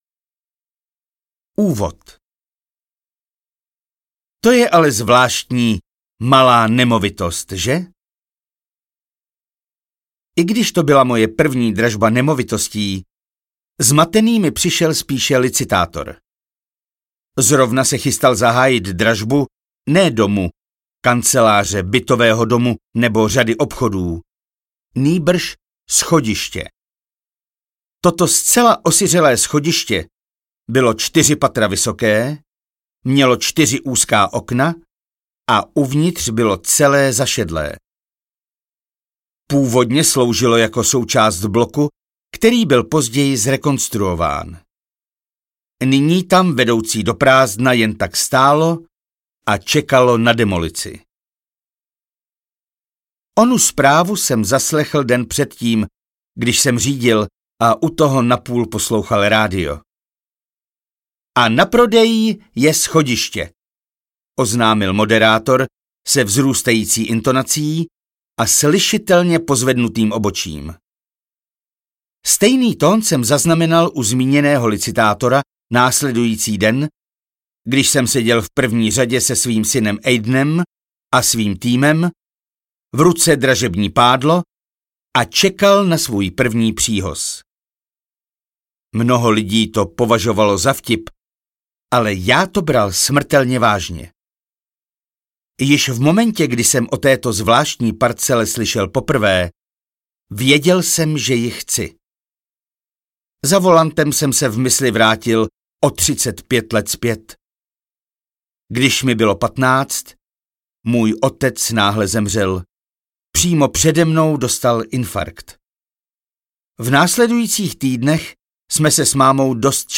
Audiokniha Jaký je váš sen? - Simon Squibb | ProgresGuru
Poslechnout delší ukázku (16 min)